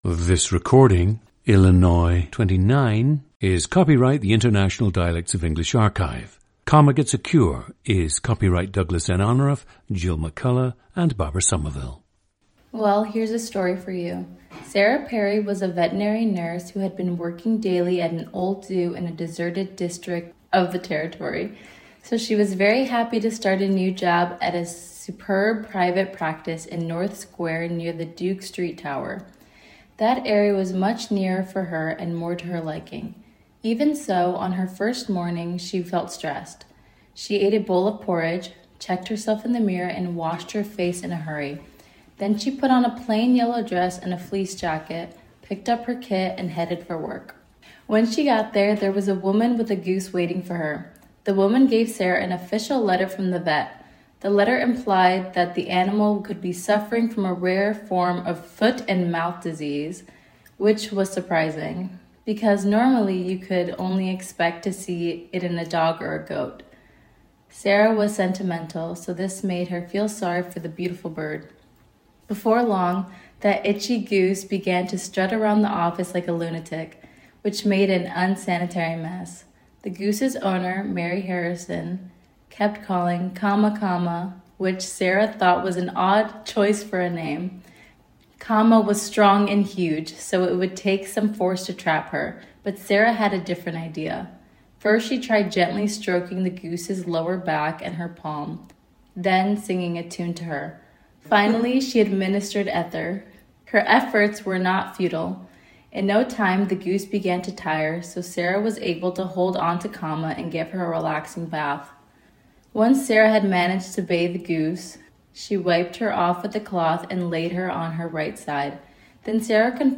GENDER: female
The subject grew up on the northwest side of Chicago with a Spanish-speaking grandmother from Mexico.
The speaker’s accent has markers of a relaxed “General American.” Note fronted U sound of “goose” and the way the L of “old” and “almost” shapes the vowel preceding it. Oral posture features lips that are quite spread (showing teeth), with a jaw and tongue that are relaxed but sitting somewhat high (making a “smile shape”).
The recordings average four minutes in length and feature both the reading of one of two standard passages, and some unscripted speech.